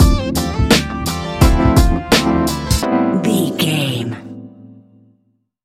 Ionian/Major
A♭
laid back
Lounge
sparse
chilled electronica
ambient
atmospheric